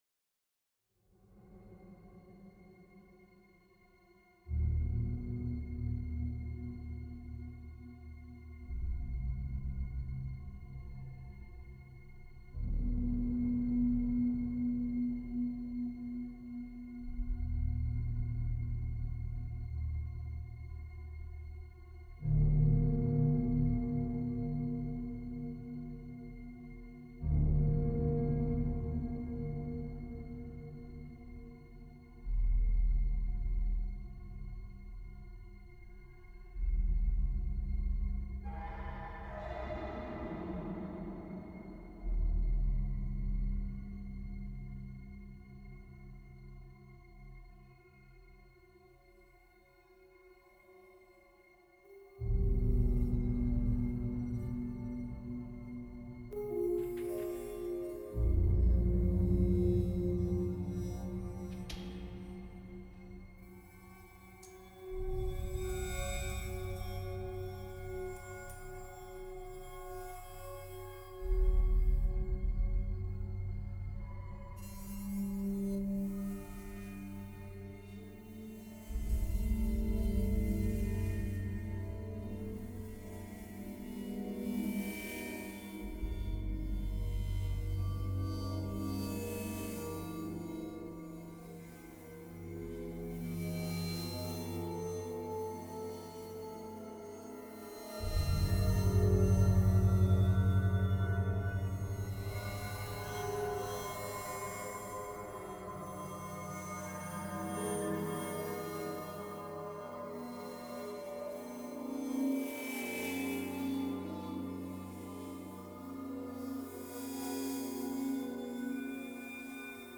Le Sanctuaire Des Nombres - Musique dite électronique.
Compositions pour neuf synthétiseurs ( Sons d’ambiance, drones et effets acoustiques ), bruits, percussions d’objets détournés pour cette utilisation, des voix très lointaines à la limite de ce qui demeure perceptible et deux pistes d’enregistrements phoniques.